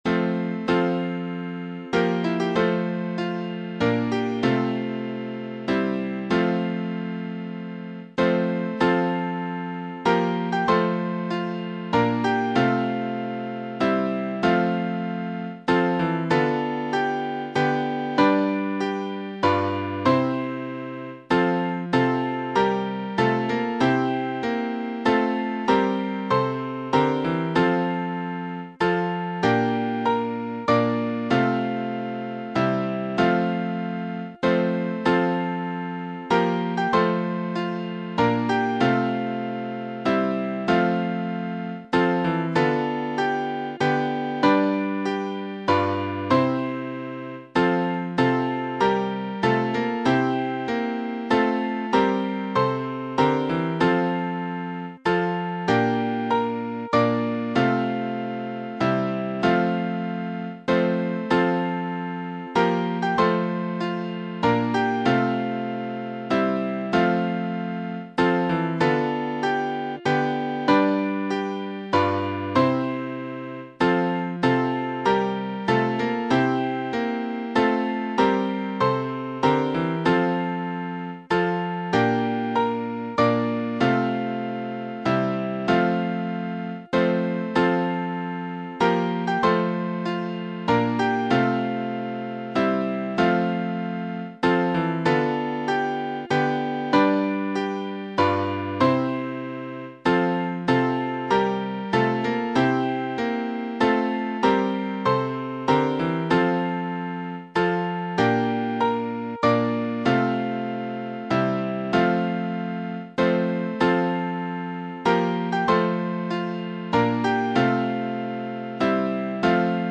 Vocals and Band   242.7kb Sung Lyrics 2.2mb